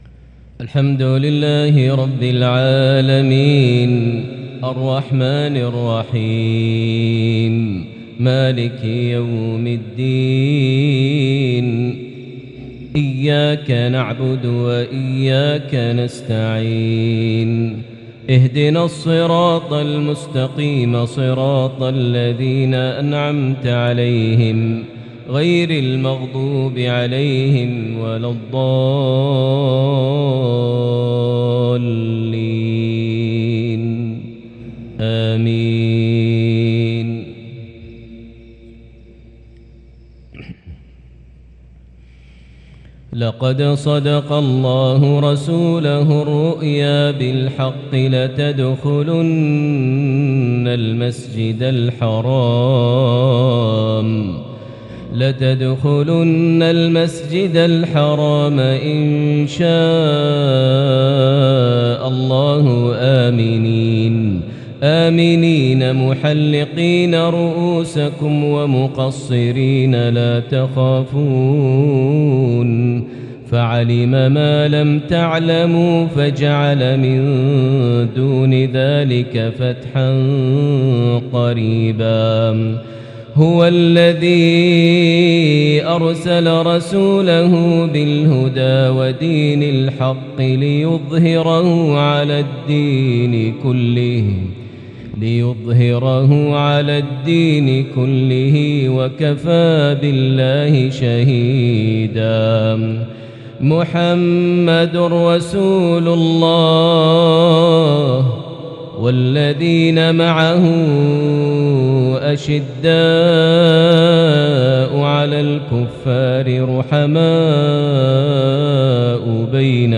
maghrib 9-5-2022 prayer from Surah Al-Fath +Surah Adh-Dhariyat > 1443 H > Prayers - Maher Almuaiqly Recitations